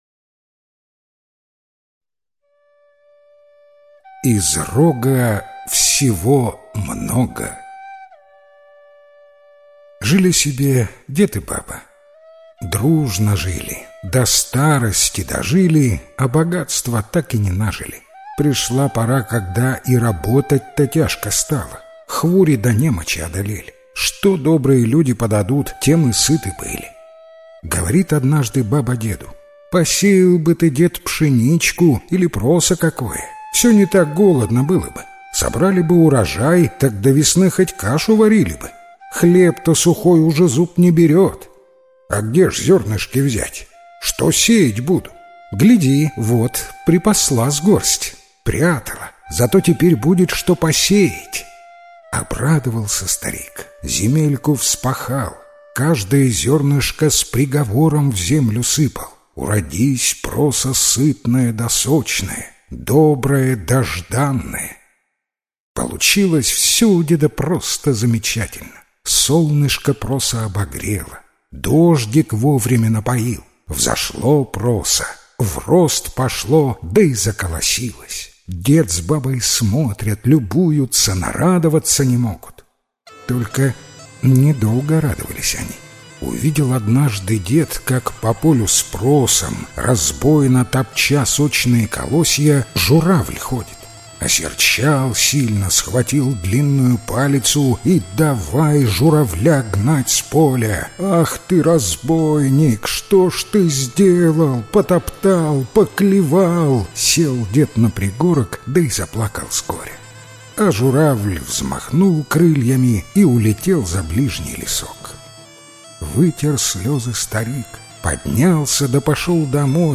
Из рога всего много - белорусская аудиосказка - слушать онлайн